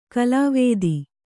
♪ kalāvēdi